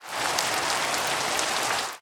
Minecraft Version Minecraft Version snapshot Latest Release | Latest Snapshot snapshot / assets / minecraft / sounds / ambient / weather / rain5.ogg Compare With Compare With Latest Release | Latest Snapshot
rain5.ogg